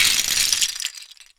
LEGO_DEBRISLRG3.WAV